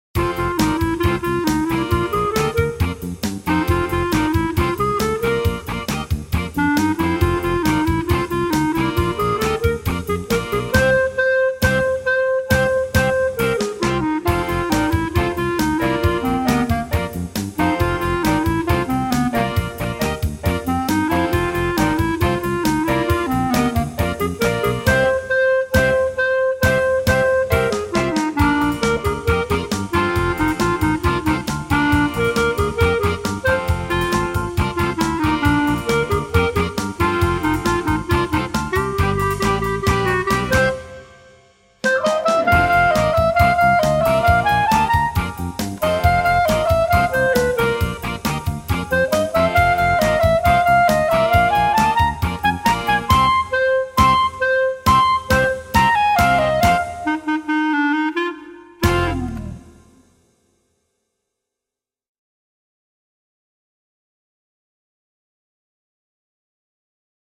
Bb Clarinet + CD (MP3s supplied free on request)